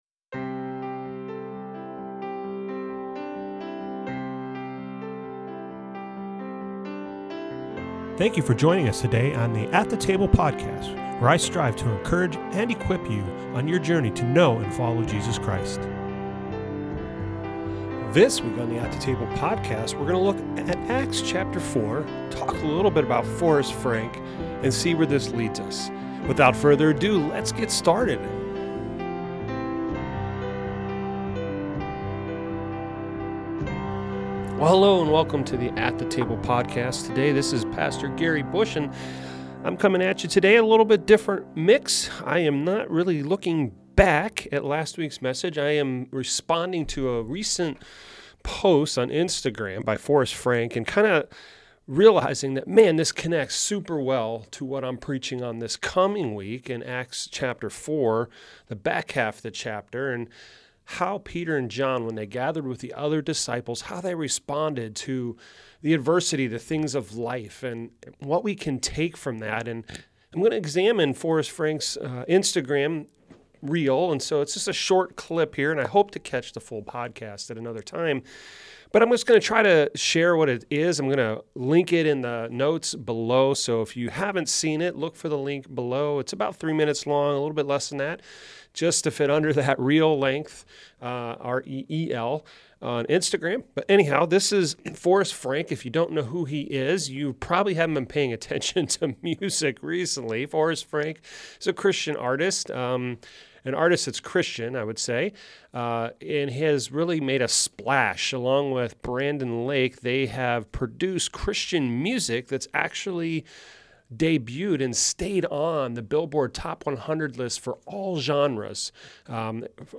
Intro and Outro music